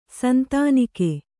♪ santānike